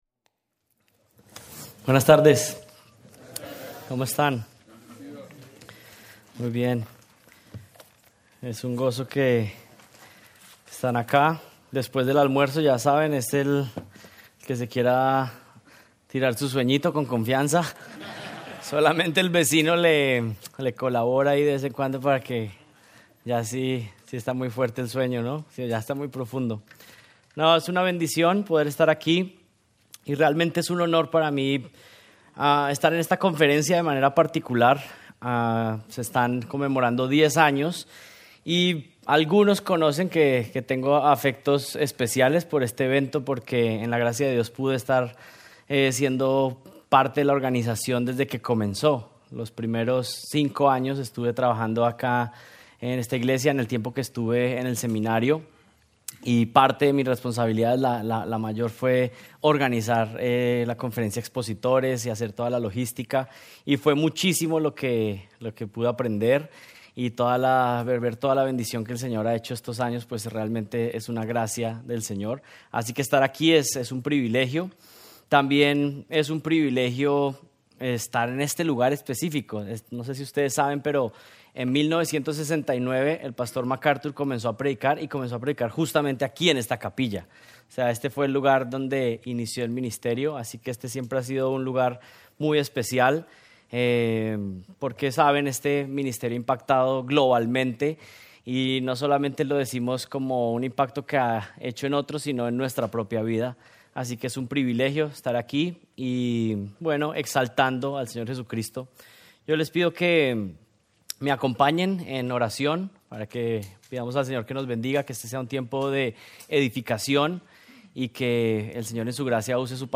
Taller: El pastor como predicador | Conferencia Expositores | Grace Community Church